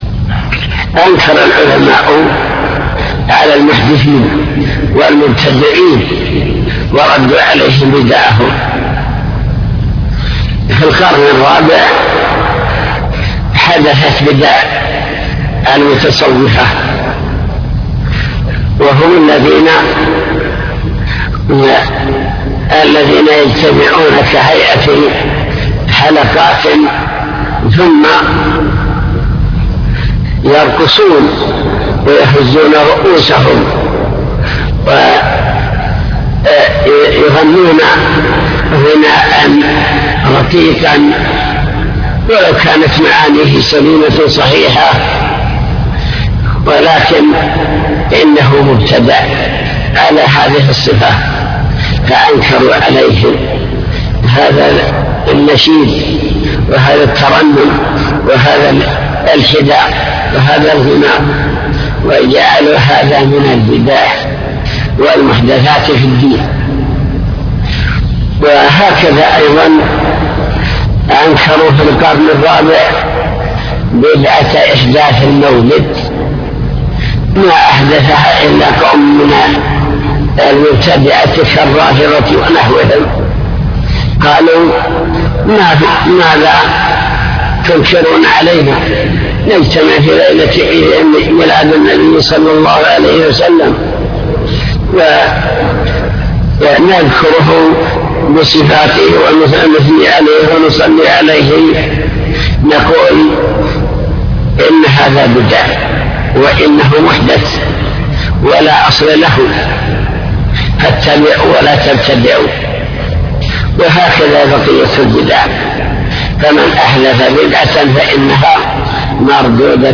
المكتبة الصوتية  تسجيلات - كتب  شرح كتاب بهجة قلوب الأبرار لابن السعدي شرح حديث من أحدث في أمرنا هذا ما ليس منه